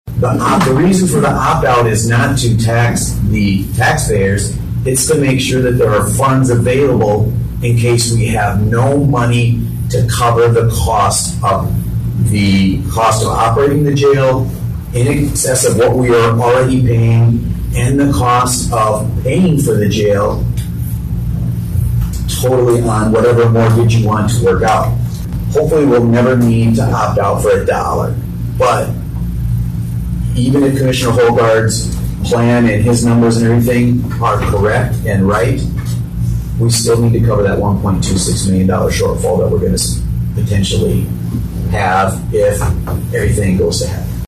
Walworth County State’s Attorney Jamie Hare clarified the reason for the opt-out.